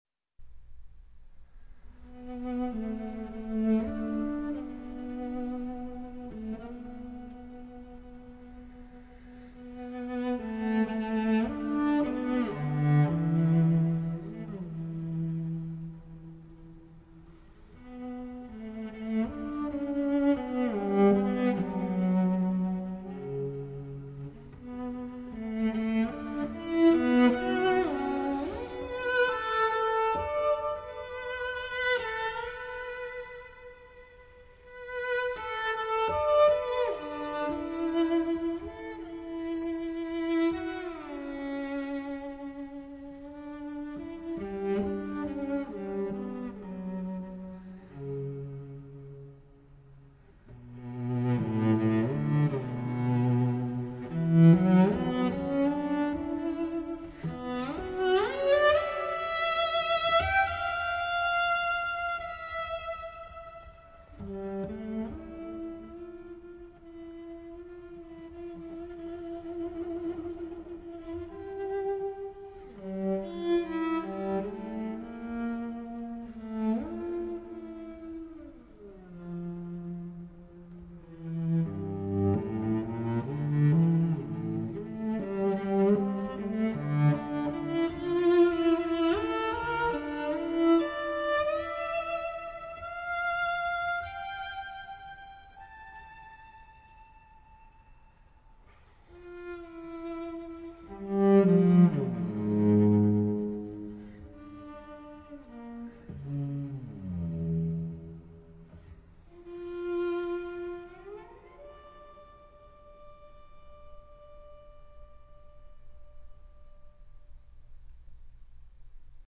В том числе и благодаря красивой инструментальной музыке.